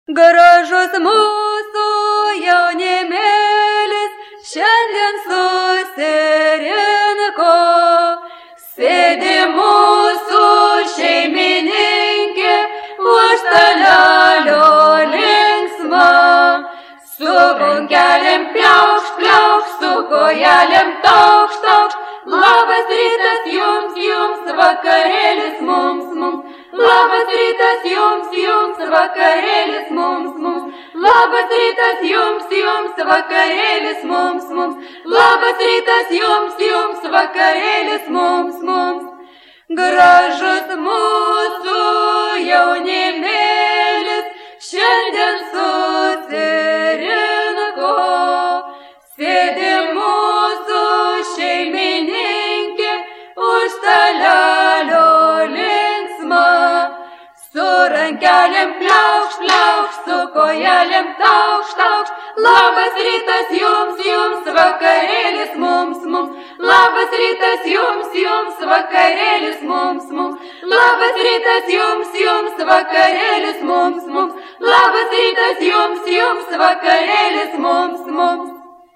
It is a popular circle in the evening parties and other entertainments. The movements illustrate the text, which is sung.
The music consists of three parts: the first part is slow, it consists of 8 bars, time 3/4; the second part is calm, it consists of 4 bars, time 2/4; the third is livelier, time 2/4; the melody of four bars is repeated as many times as wanted.